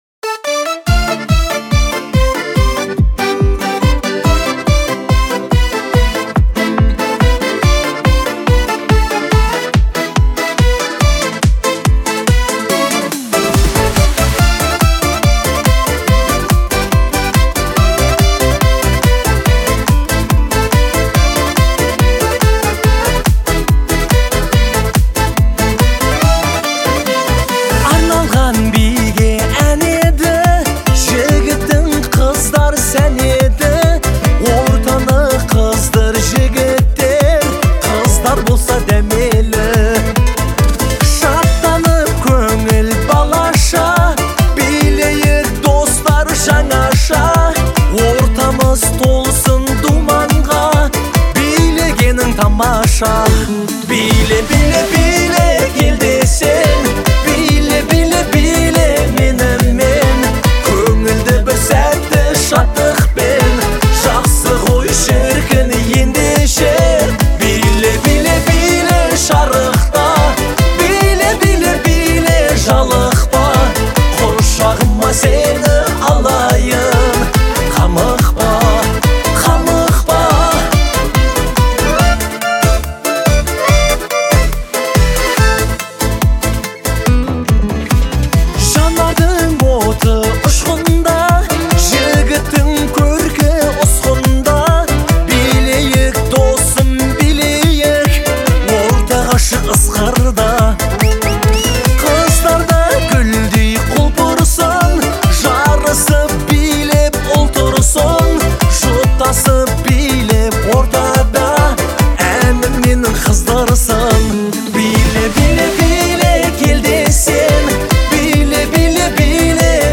• Жанр: Казахские песни